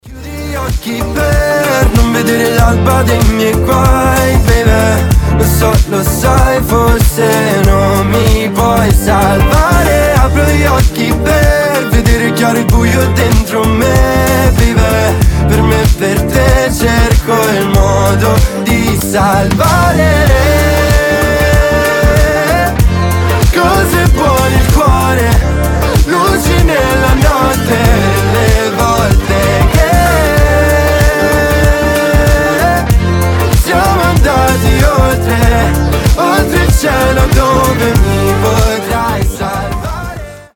• Качество: 320, Stereo
красивый мужской голос
итальянские